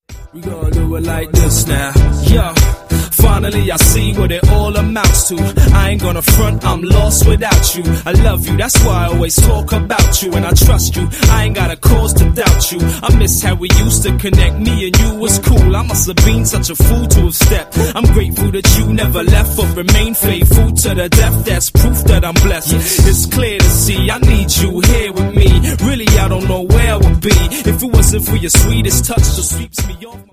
• Sachgebiet: Urban